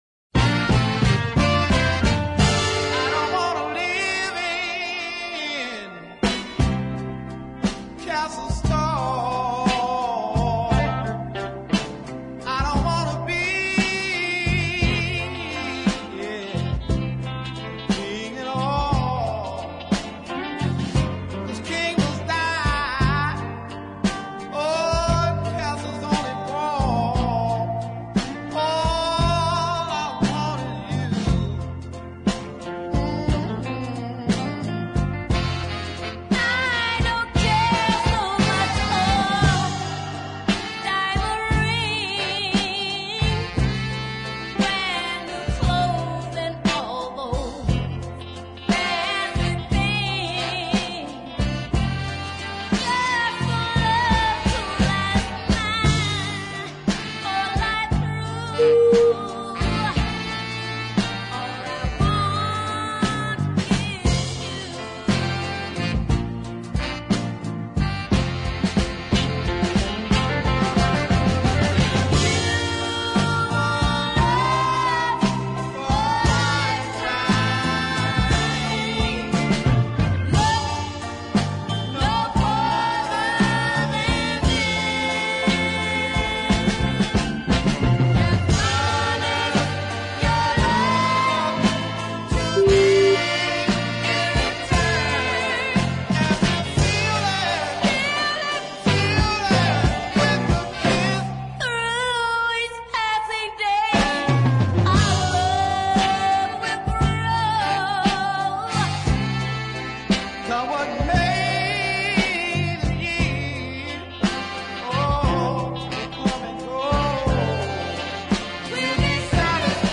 they did cut just this one 45 at Muscle Shoals
The slow burn top side